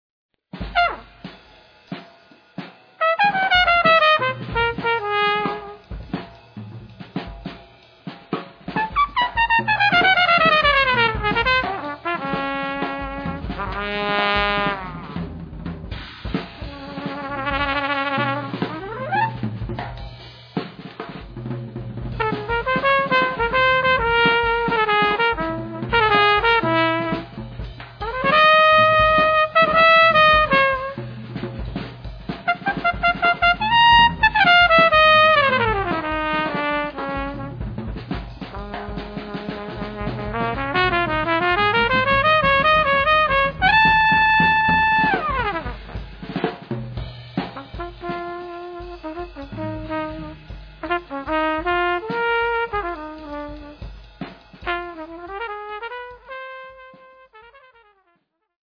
Jazz
free jazz to the core